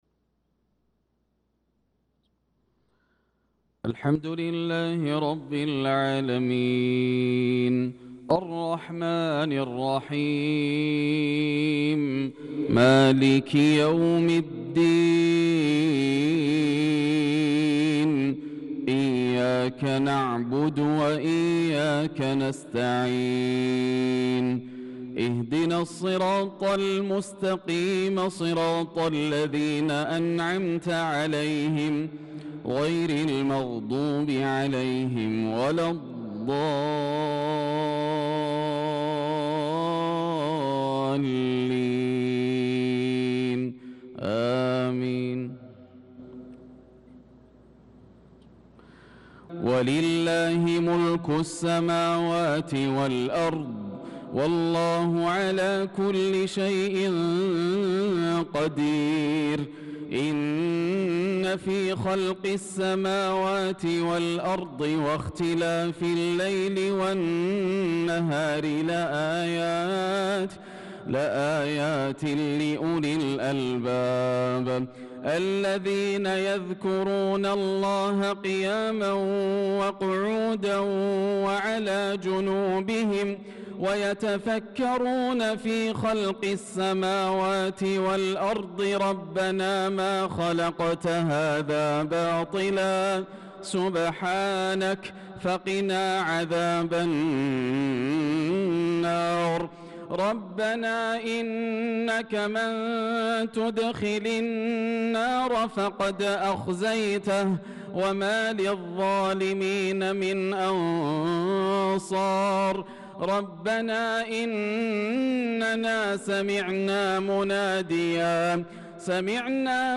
صلاة العشاء للقارئ ياسر الدوسري 17 ذو القعدة 1445 هـ
تِلَاوَات الْحَرَمَيْن .